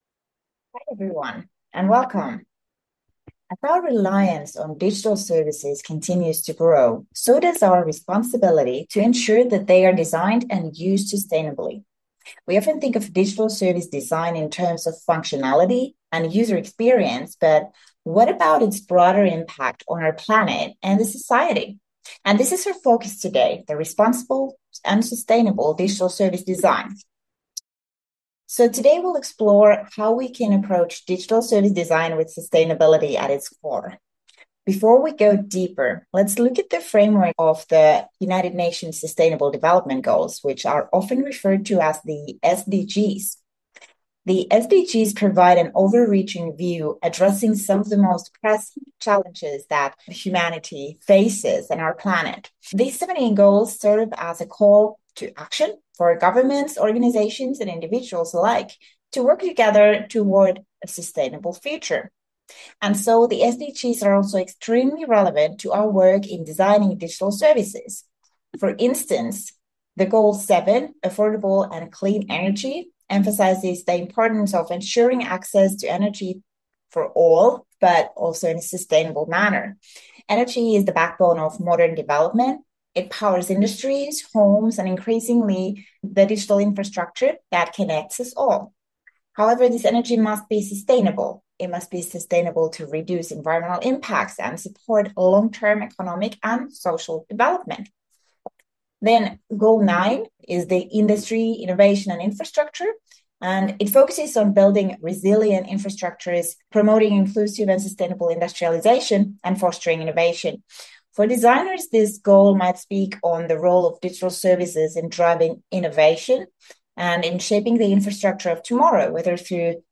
Week 4 Self-Study Video Lecture - Responsible and Sustainable Digital Service Design